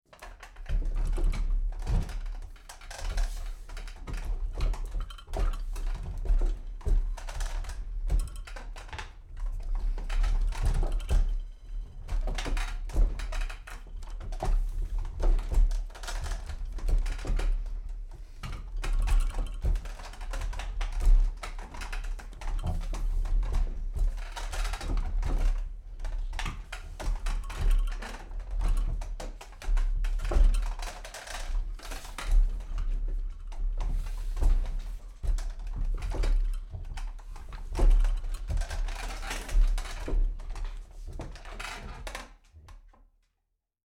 Вы можете слушать и скачивать мощные удары по стенам, грохот бронзового наконечника и другие эффекты, воссоздающие атмосферу средневековых битв.
Звук тарана, едущего по земле